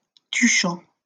Tuchan (French pronunciation: [tyʃɑ̃]